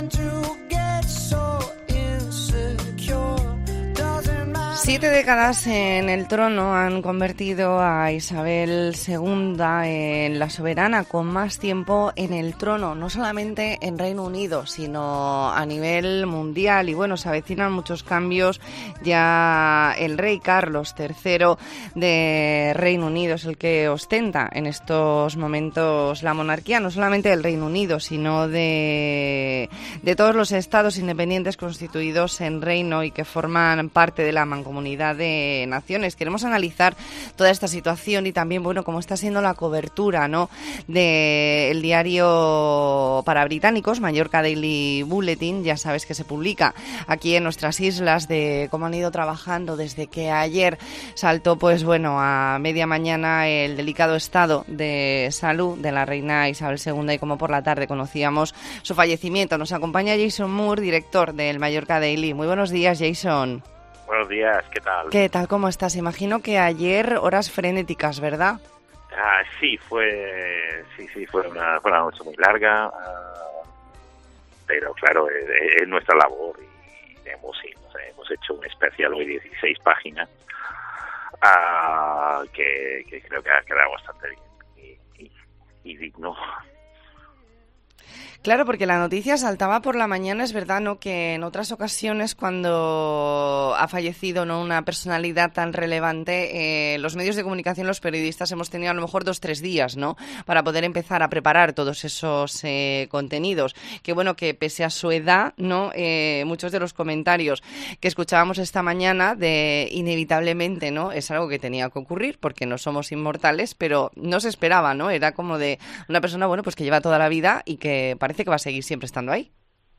ntrevista en La Mañana en COPE Más Mallorca, viernes 9 de septiembre de 2022.